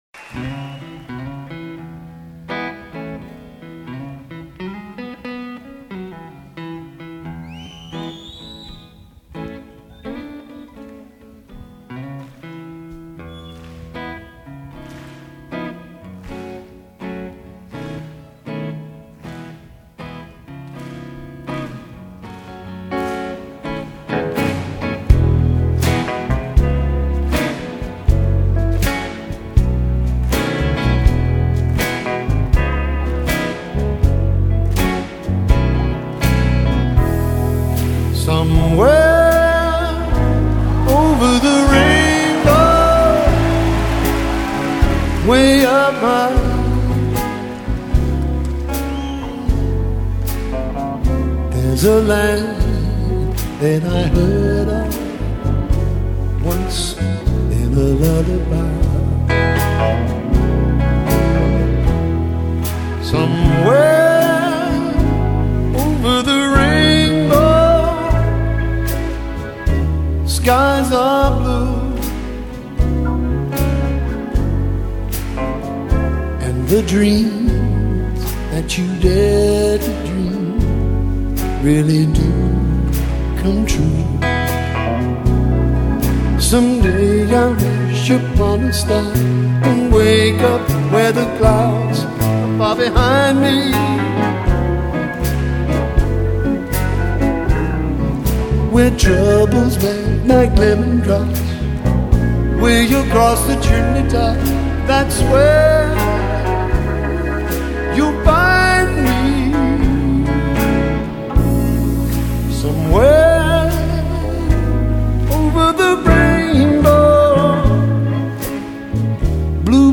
吉他大师珍贵的现场版本,学吉他的朋友不可错过!